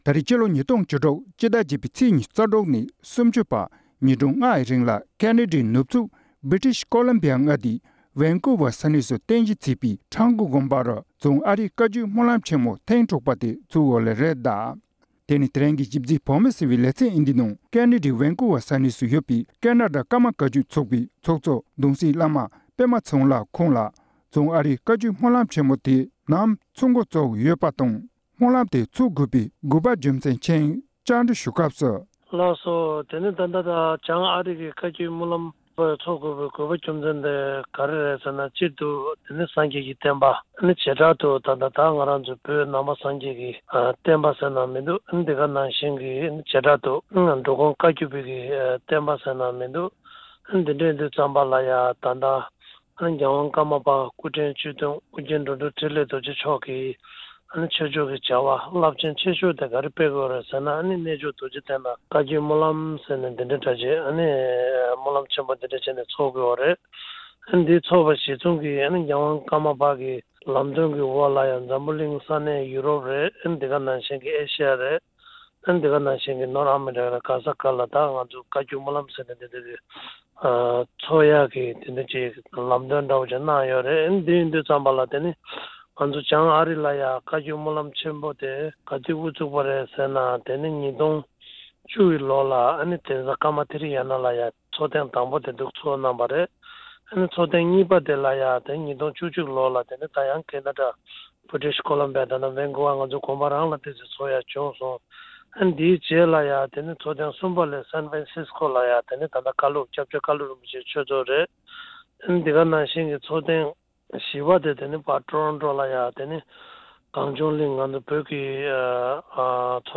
བཅའ་འདྲི་ཞུས་ནས་ཕྱོགས་སྒྲིག་ཞུས་པ་གསན་རོགས